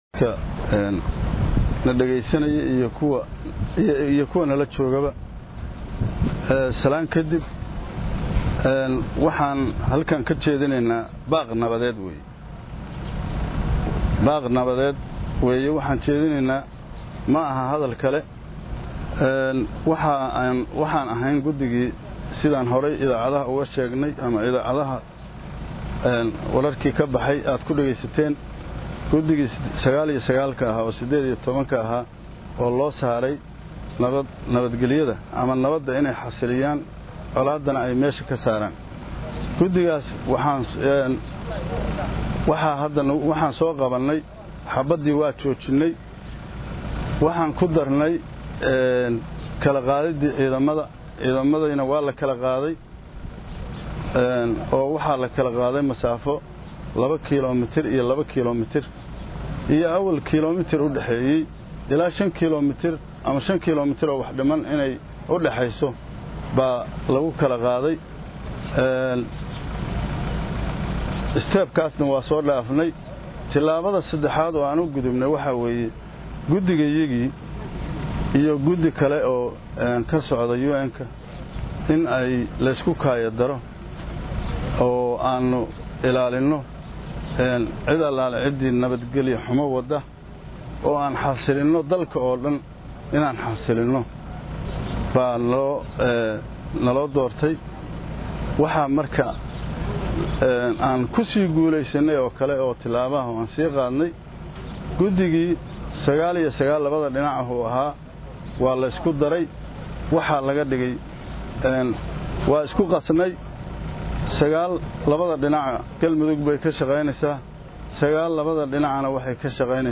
19 nov 2016 (Puntlandes) Gudoomiyaha Gobolka Mudug Xasan Maxamed Khaliif (Xasan Abgaaloow) oo saxaafadda la hadlay kadib kulankii ay maanta yeesheen madaxda Dowladda Goboleedyada iyo dhex dhexaadiyaashii, DFS, QM iyo IDAG, ayaa ka dhawaajiyey in ay haatan ka gudbeen wajigii koowaad.